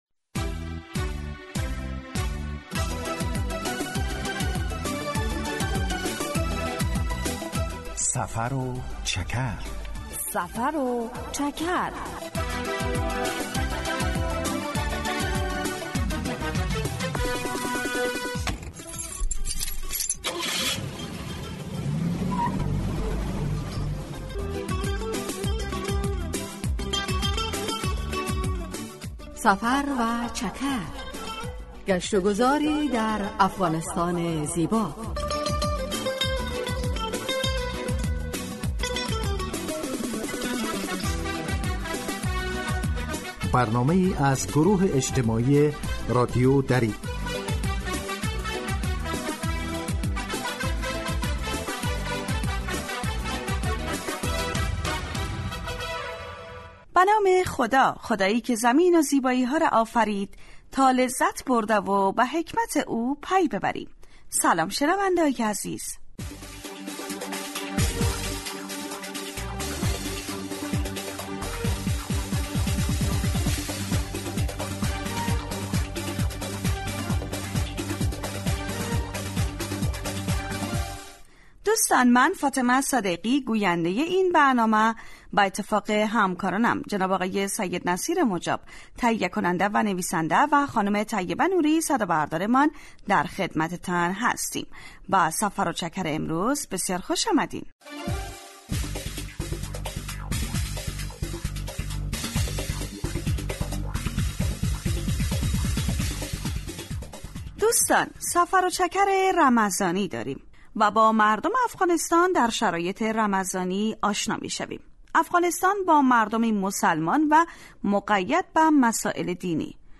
در سفر و چکر ؛ علاوه بر معلومات مفید، گزارش و گفتگو های جالب و آهنگ های متناسب هم تقدیم می شود.